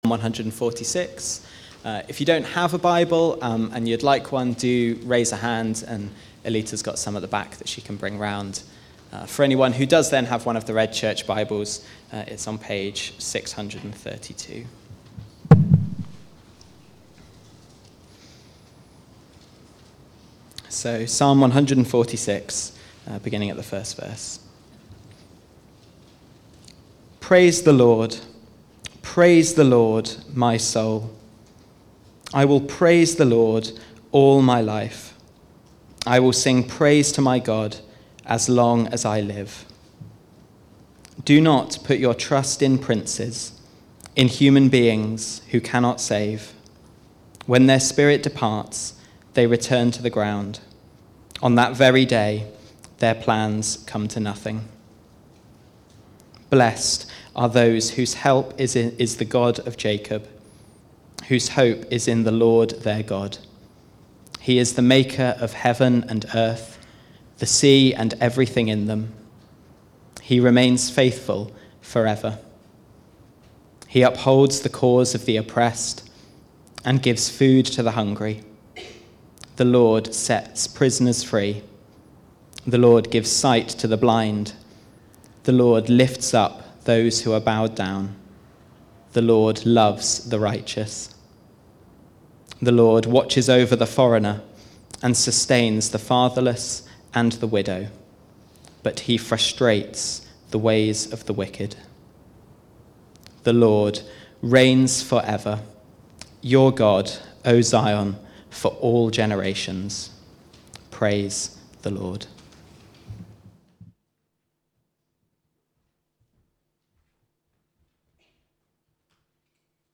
Praise the Lord (Psalm 146) Recorded at Woodstock Road Baptist Church on 12 June 2022.